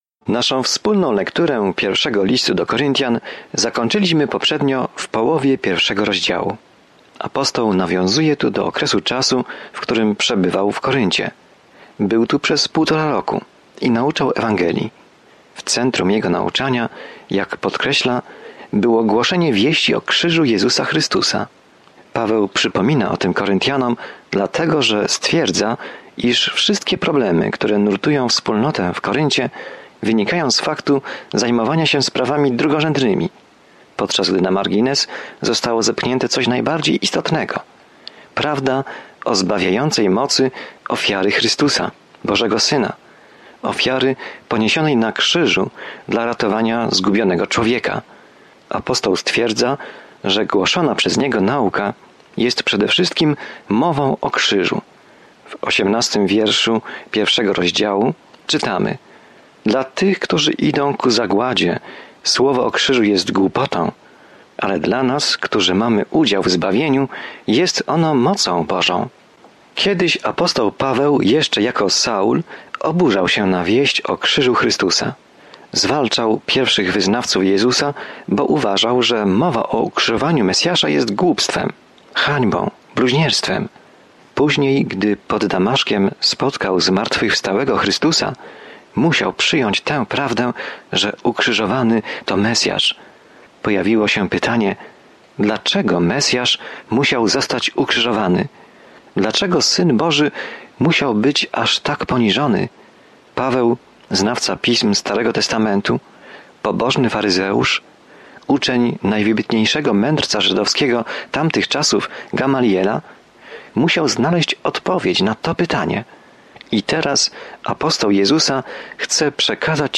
Jest to temat poruszony w Pierwszym Liście do Koryntian, zawierający praktyczną opiekę i korektę problemów, przed którymi stają młodzi chrześcijanie. Codziennie podróżuj przez 1 List do Koryntian, słuchając studium audio i czytając wybrane wersety słowa Bożego.